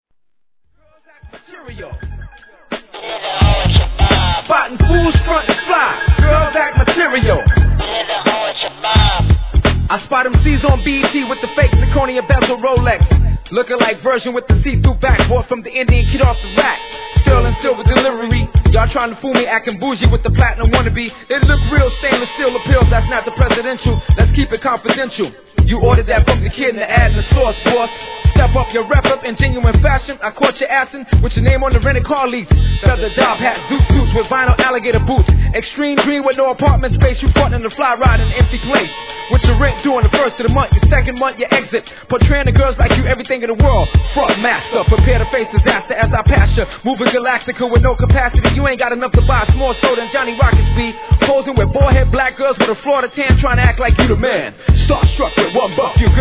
HIP HOP/R&B
相変わらずなダーク・サウンドもGOOD!!